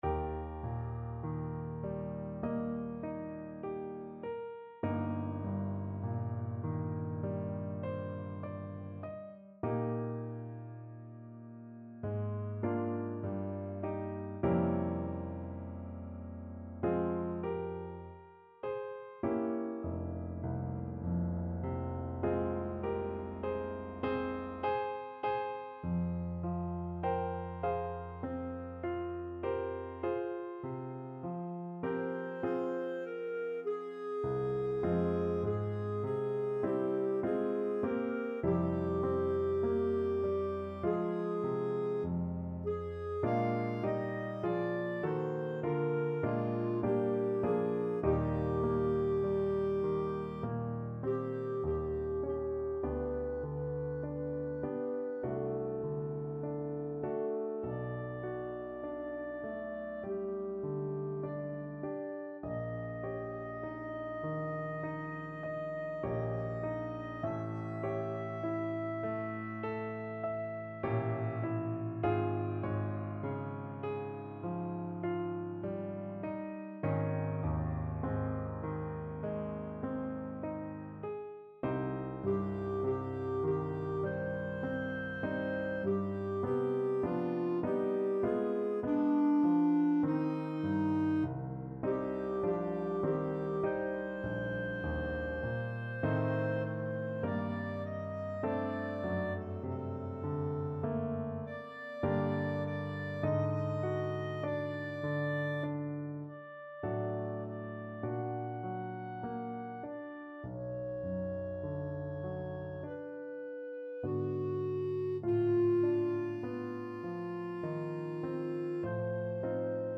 ~ = 50 Tranquillo
4/4 (View more 4/4 Music)
D5-Ab6
Classical (View more Classical Clarinet Music)